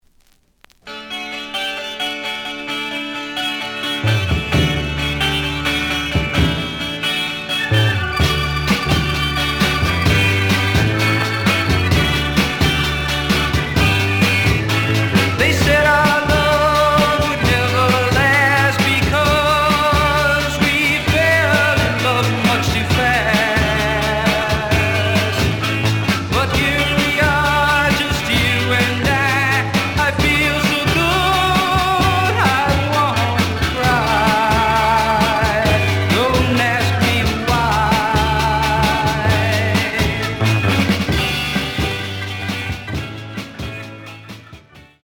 試聴は実際のレコードから録音しています。
●Genre: Rock / Pop